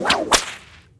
blink_01v2.wav